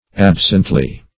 Absently \Ab"sent*ly\, adv.